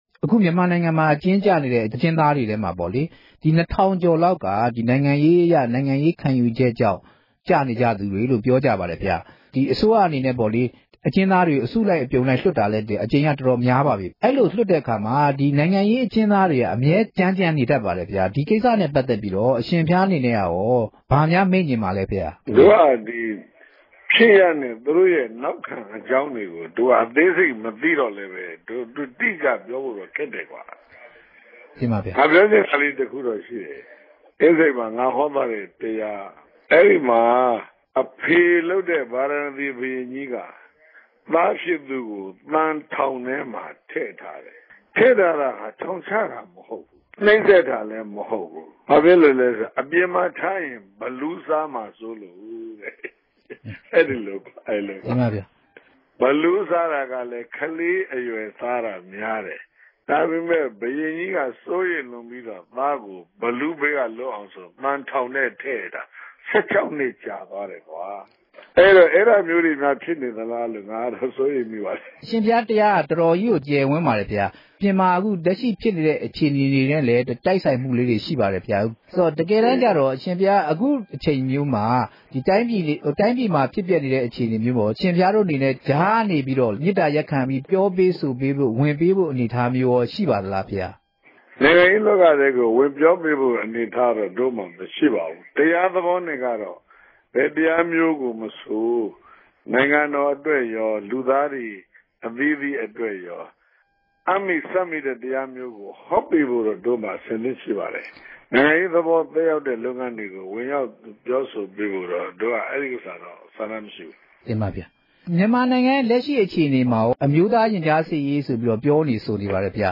လ္တေွာက်ထားမေးူမန်းခဵက်။